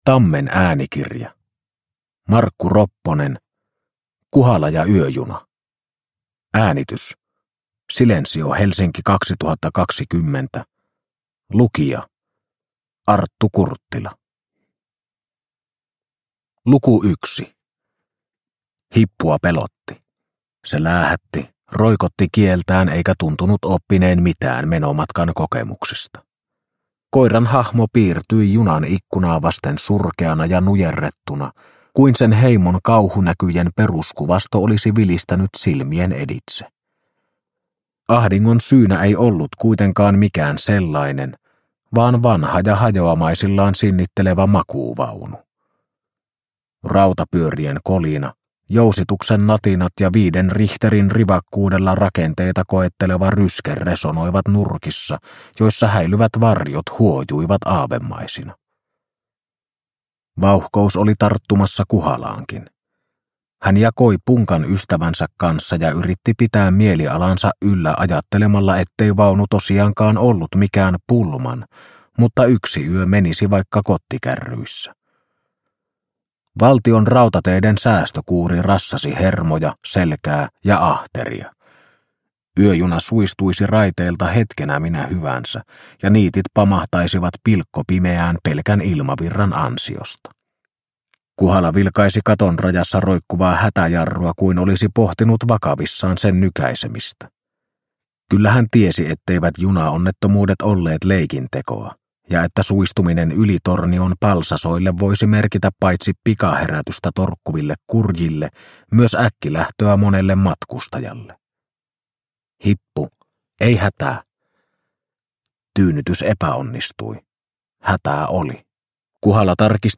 Kuhala ja yöjuna – Ljudbok – Laddas ner